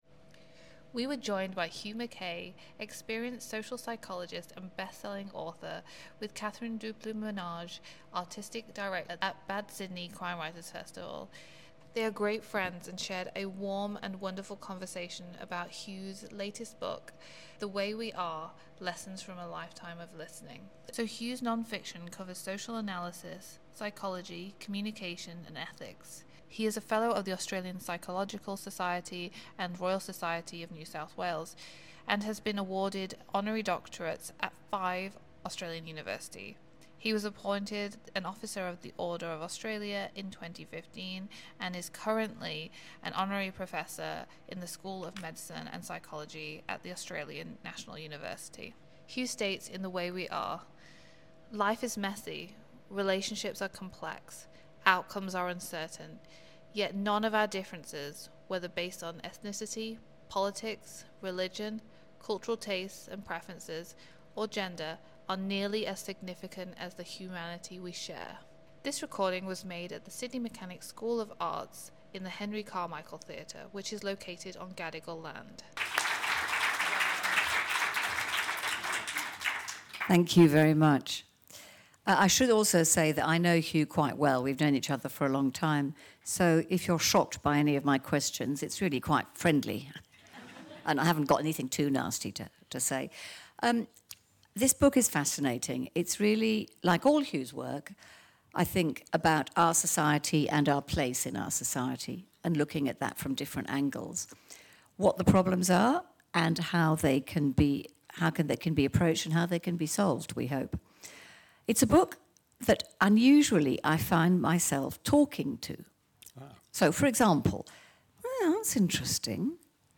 Event Recording: Life lessons from Hugh Mackay, Psychologist and bestselling author
Hugh-Mackay-at-the-SMSA.mp3